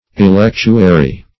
electuary - definition of electuary - synonyms, pronunciation, spelling from Free Dictionary
Electuary \E*lec"tu*a*ry\ (?; 135), n.; pl. Electuaries. [OE.